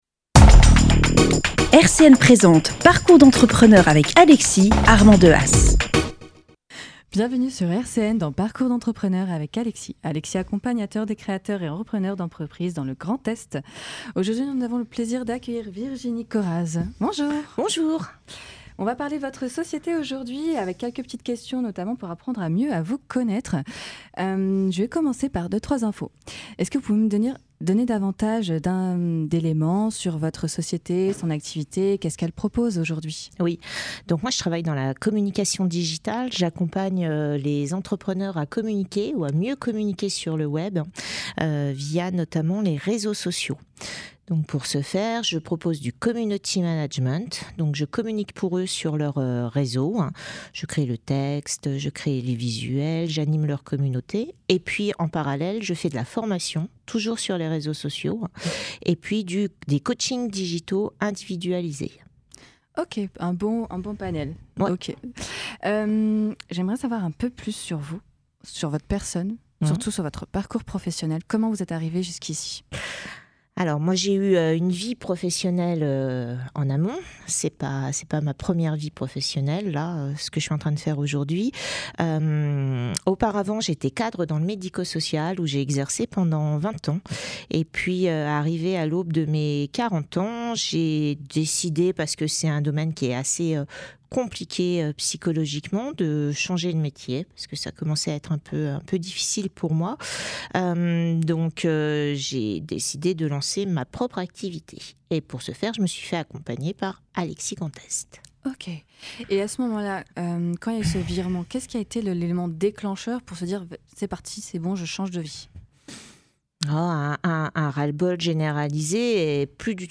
Le 25 mars 2024, RCN a organisé un after work dans ses locaux pour présenter aux entrepreneurs et chefs d’entreprises le média son fonctionnement et enregistrer une émission.